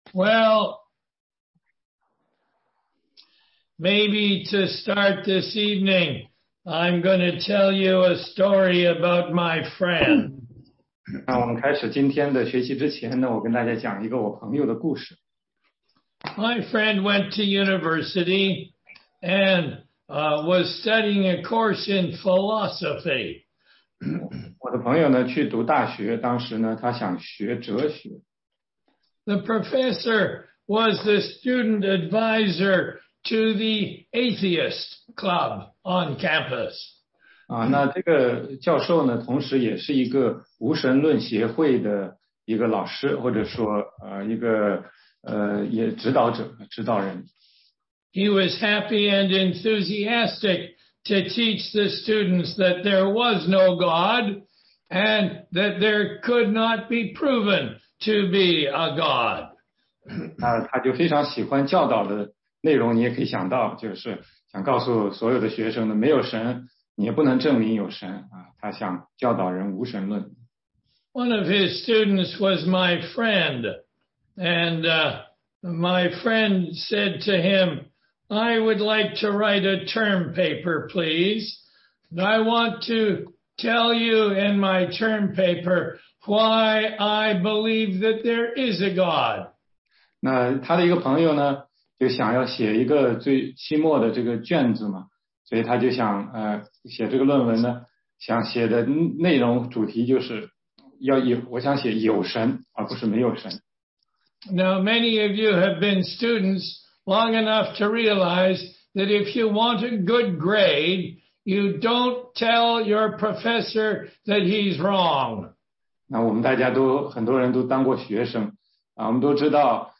16街讲道录音 - 福音课第四十七讲